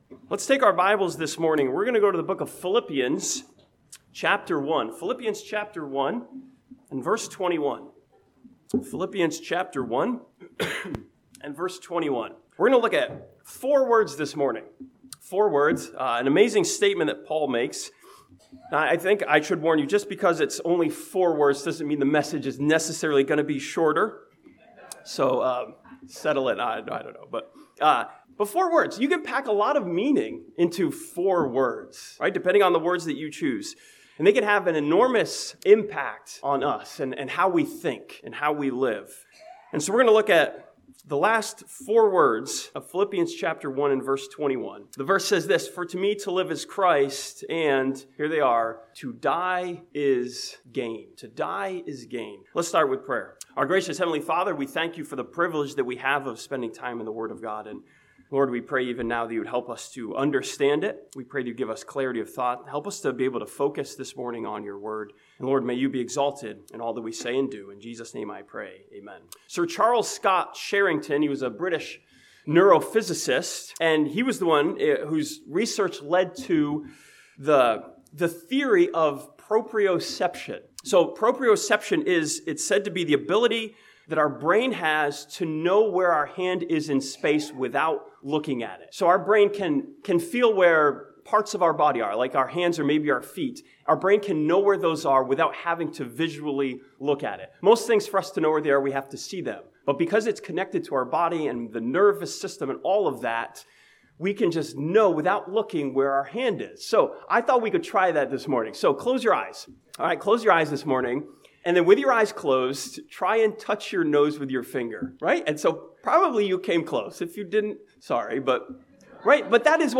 This sermon from Philippians chapter 1 looks at the world changing statement "to die is gain" in light of the resurrection.